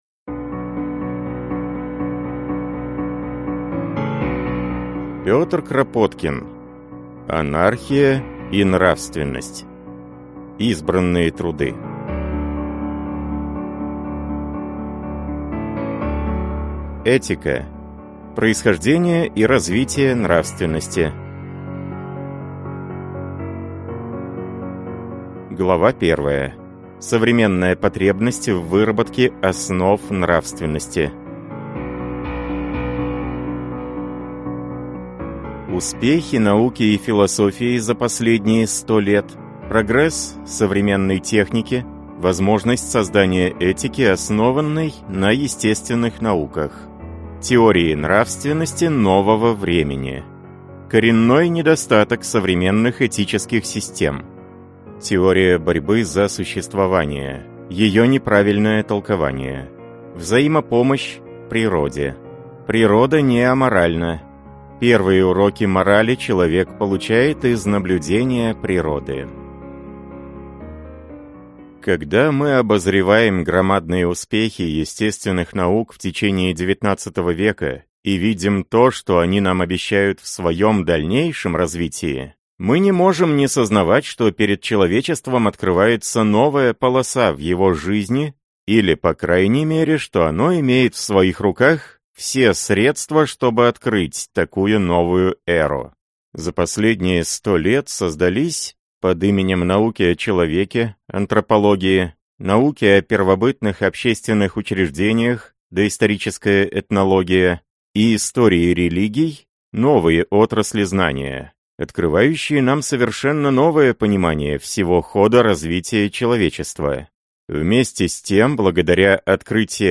Аудиокнига Анархия и нравственность. Избранные труды | Библиотека аудиокниг